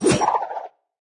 Media:Medic_base_atk_1.wav 攻击音效 atk 初级和经典及以上形态攻击或投掷治疗包音效